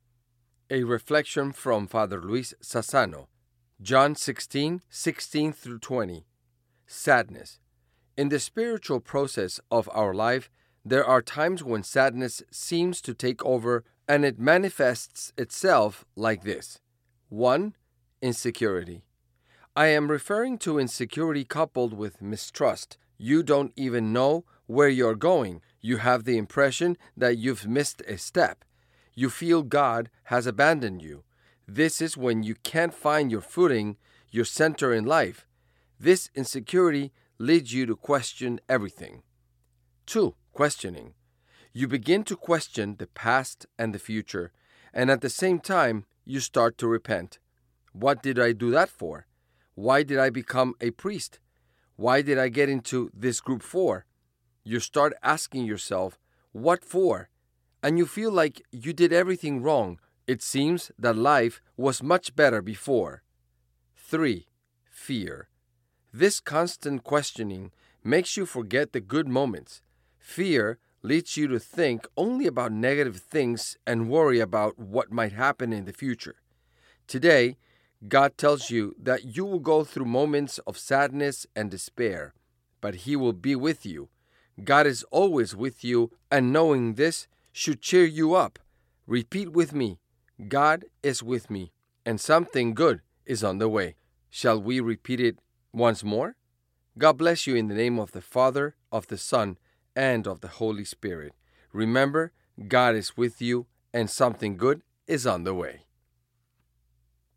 Daily Meditation